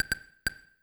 TEC Blip 2 G.wav